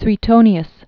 (swē-tōnē-əs) Full name Gaius Suetonius Tranquillus. fl. second century AD.